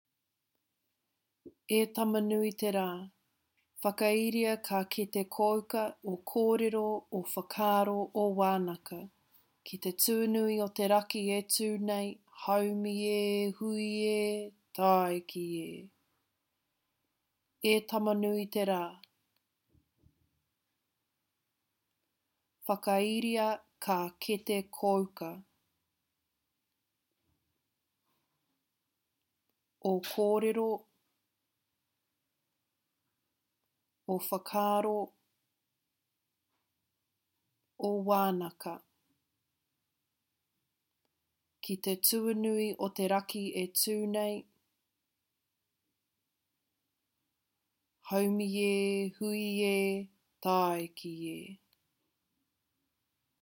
Additionally, audio recordings of the Waiata, Karakia Timatanga, and the Karakia Whakakapi have been provided to assist in learning proper pronunciation.
Karakia-Whakakapi.mp3